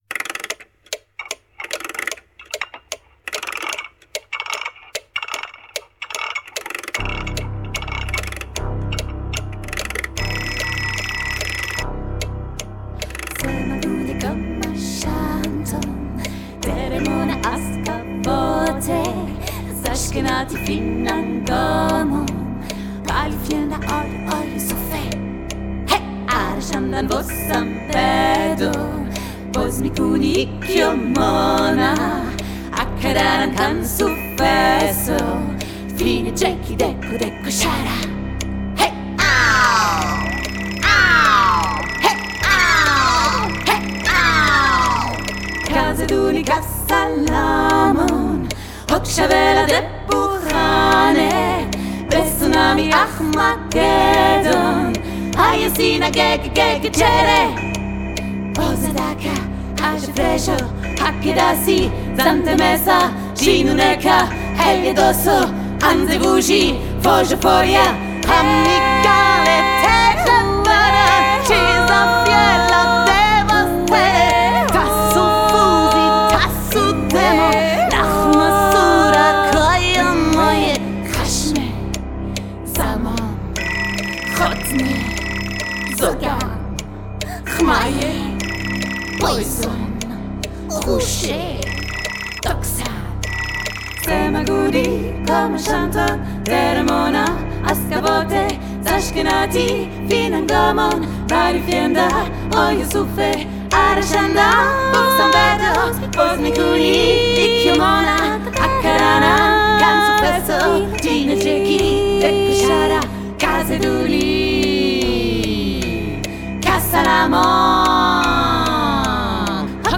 Percussions